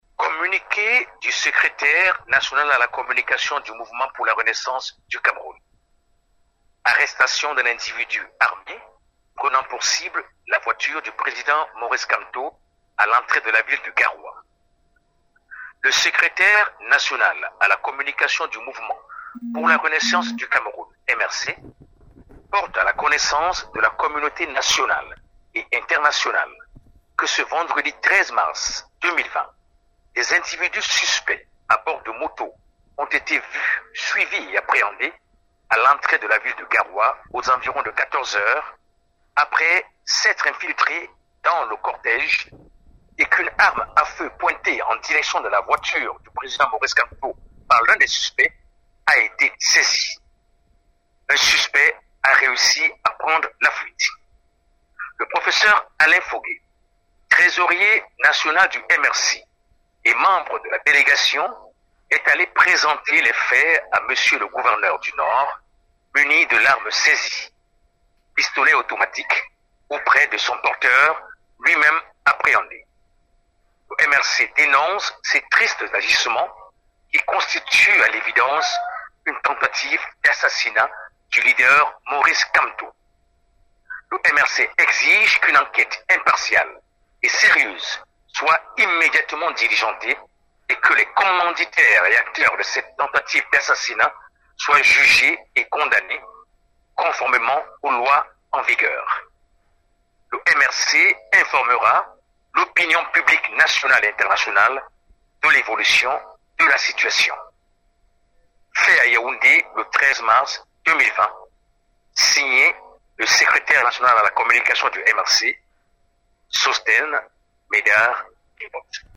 communique_du_mrc.mp3